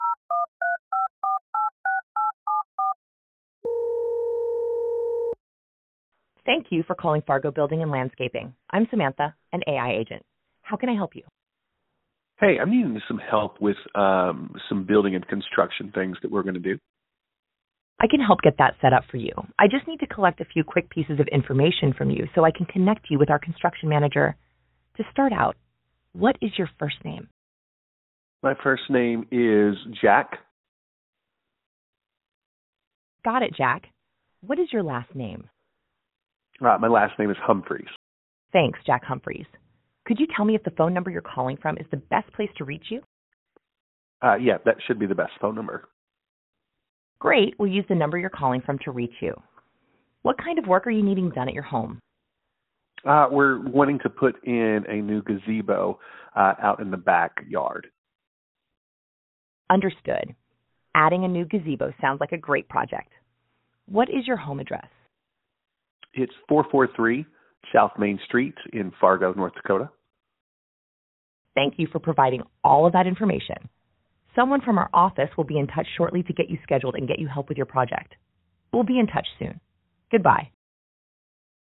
Click to Listen to a Demo Call with an AI Receptionist